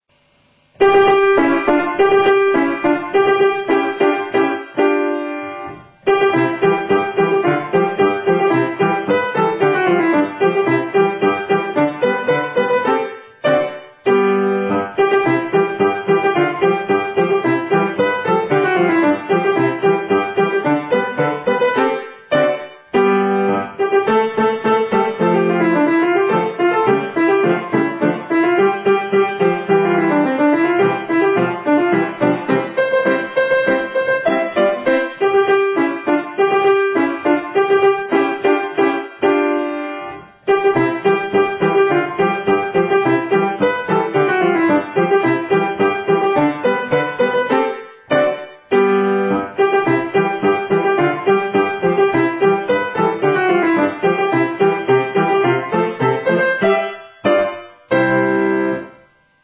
今回は、ピアノ用にアレンジされた楽譜を使用して演奏しました。